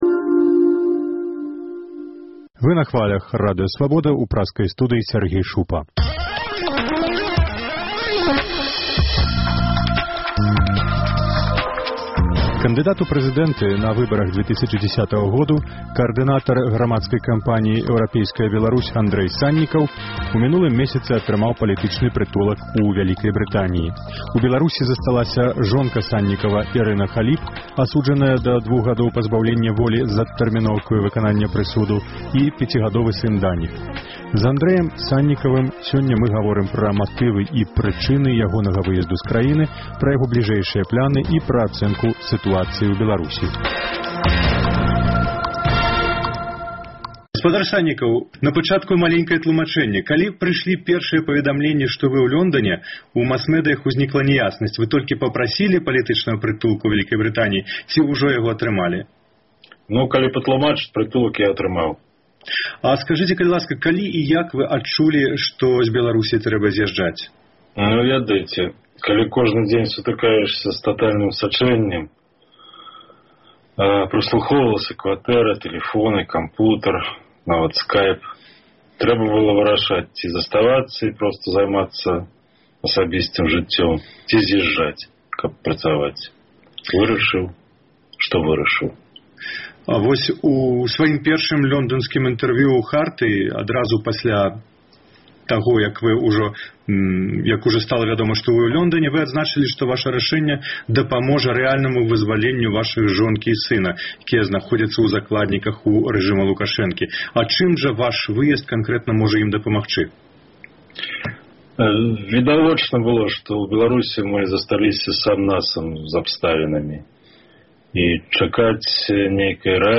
Інтэрвію з кандыдатам у прэзыдэнты на выбарах 2010 году Андрэем Саньнікавым.